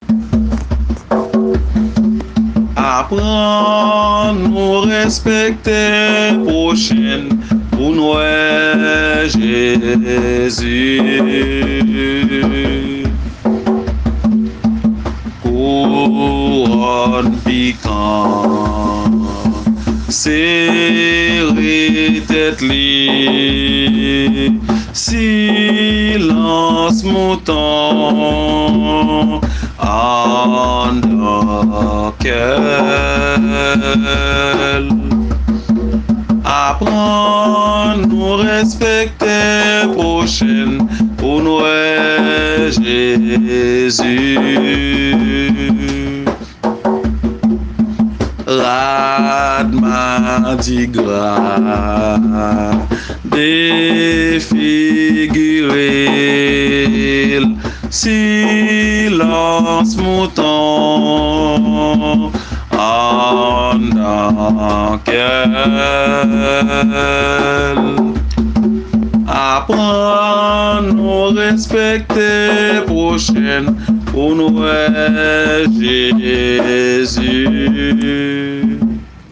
Kantik Kréyòl